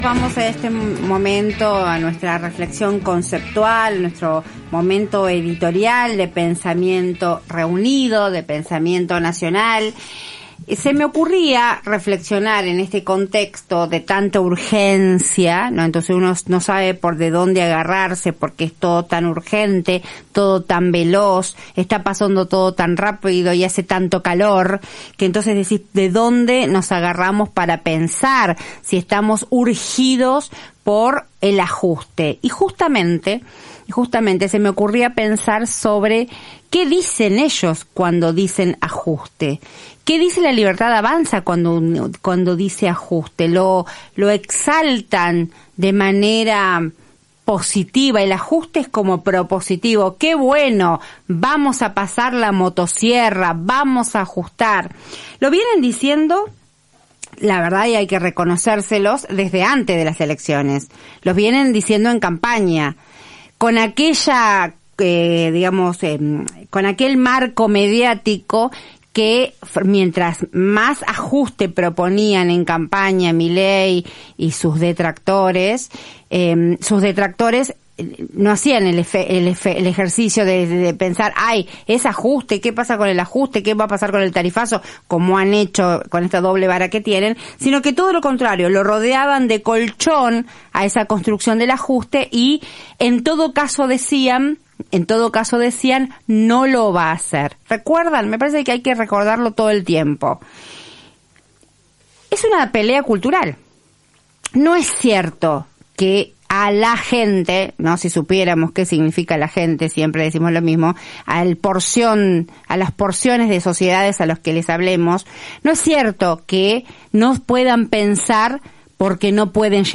En su editorial la periodista y conductora, la política de ajuste económico de Javier Milei y consideró central volver a poner sobre la mesa los debates sobre la identidad y las conquistas sociales conseguidas.